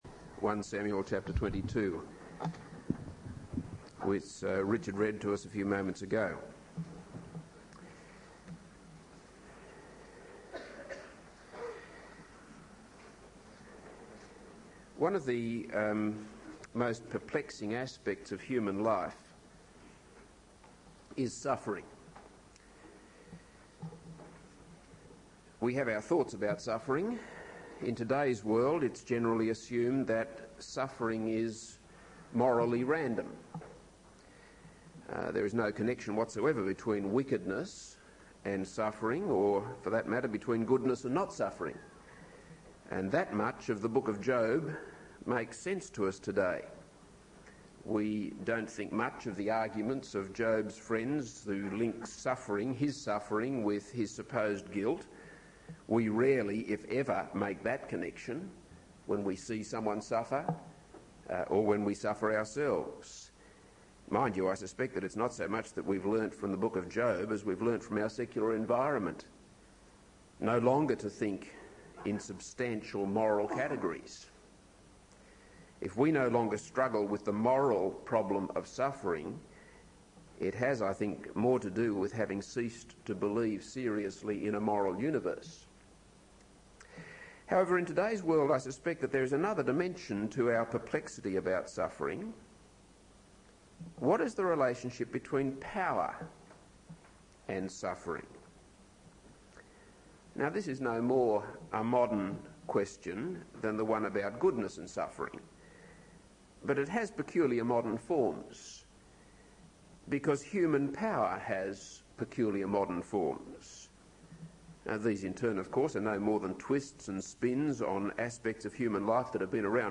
This is a sermon on 1 Samuel 22.